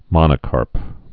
(mŏnə-kärp)